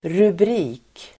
Uttal: [rubr'i:k]